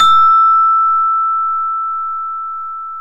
CELESTE E4.wav